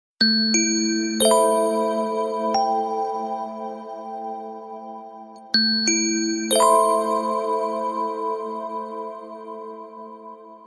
• Качество: 128, Stereo
спокойные
Завораживающие
волшебные